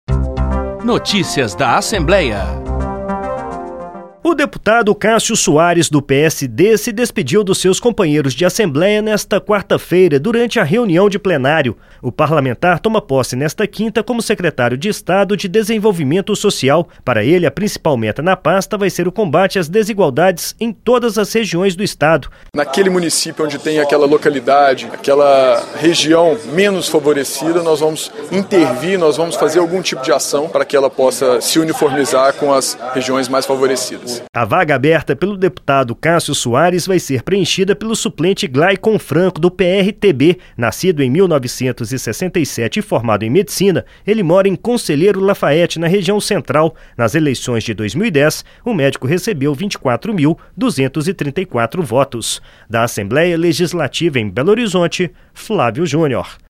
Durante a reunião do Plenário, o parlamentar se despediu dos companheiros para assumir o comando da Secretaria de Estado de Desenvolvimento Social.